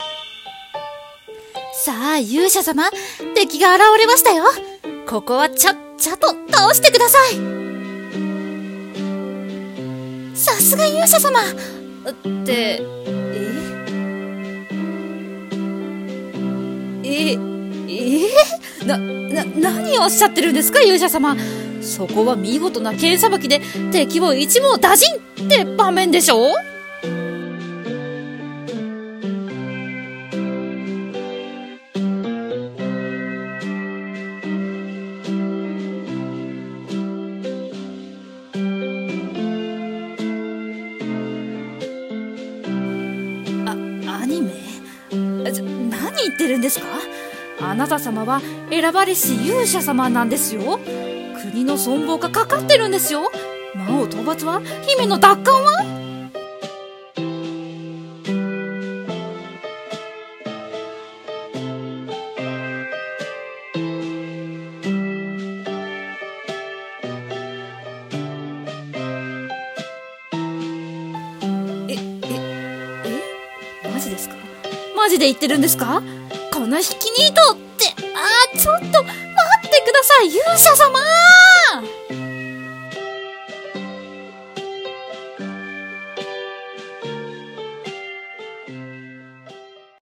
【声劇】やる気のない勇者【掛け合い】